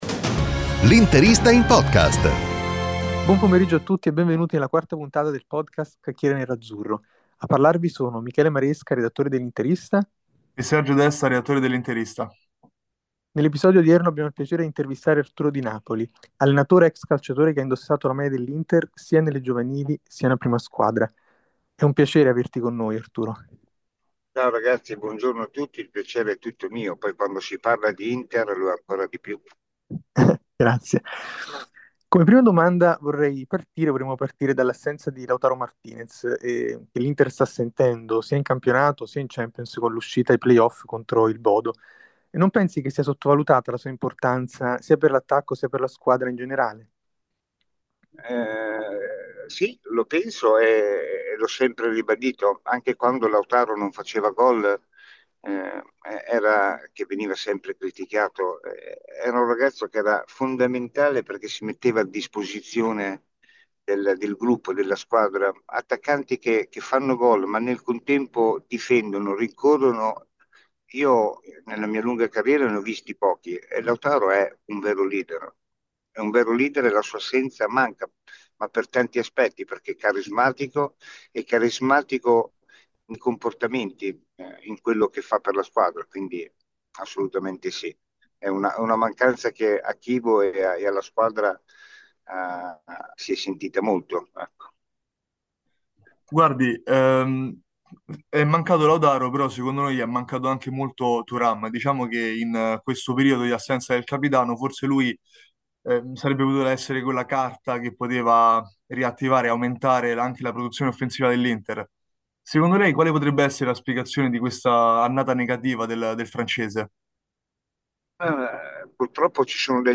intervistare